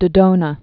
(də-dōnə)